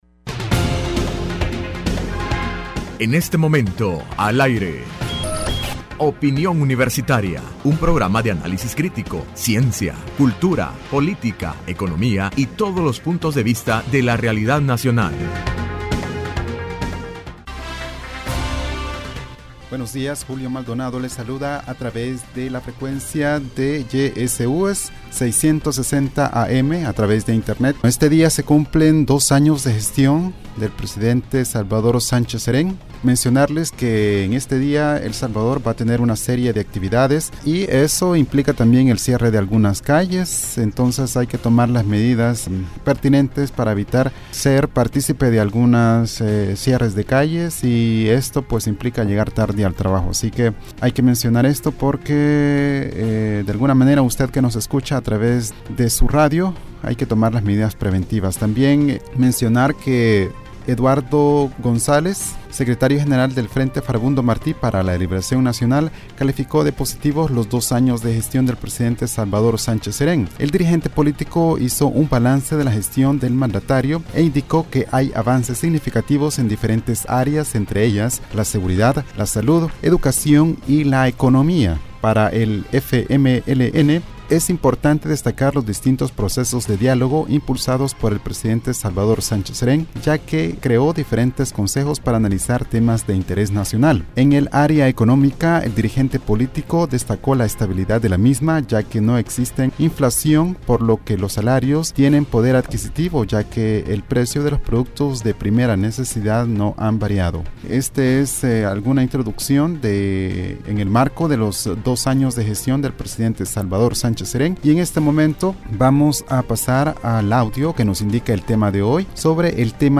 Entrevista Opinión Universitaria (1 Junio 2016) : Empuje de la economía salvadoreña a través de las remesas.